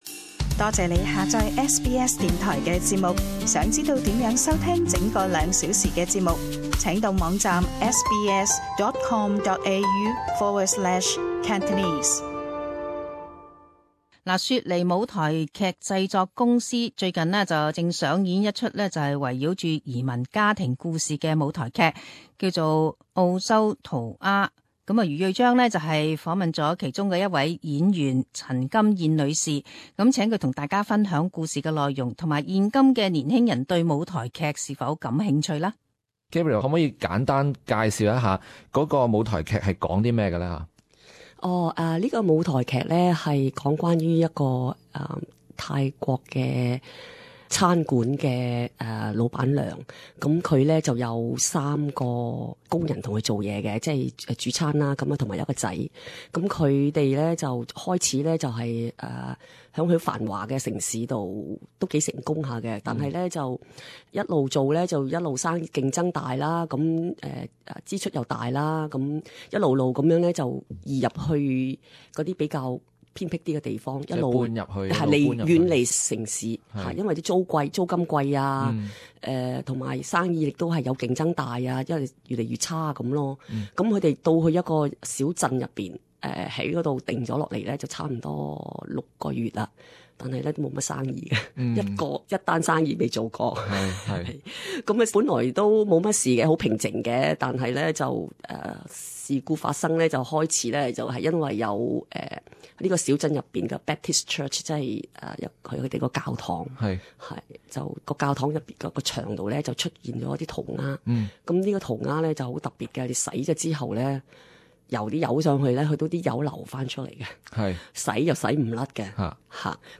【社區專訪】舞台劇演繹移民家庭的內心掙扎